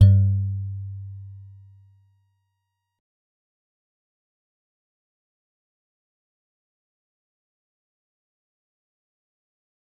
G_Musicbox-G2-mf.wav